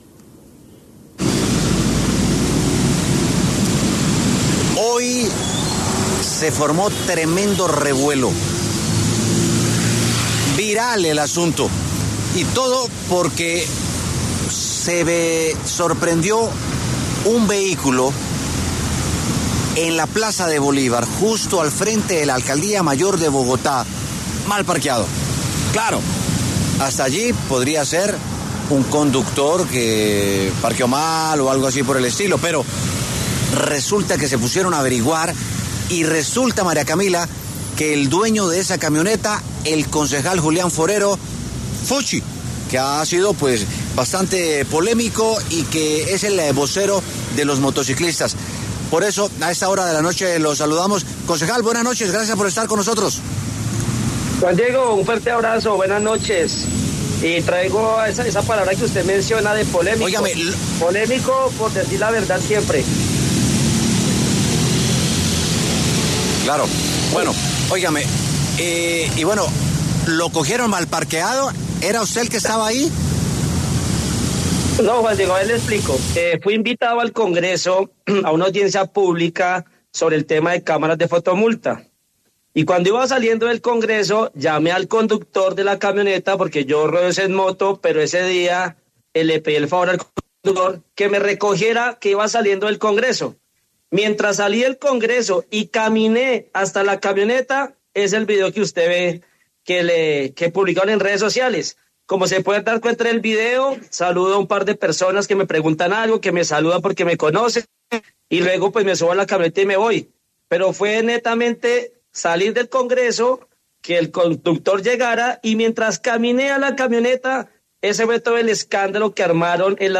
El concejal Julián ‘Fuchi’ Forero pasó por los micrófonos de W Sin Carreta para hablar acerca de la polémica que se desató por tener mal parqueada su camioneta en frente de la Alcaldía de Bogotá.